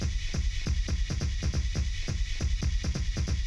BACKROUND -L.wav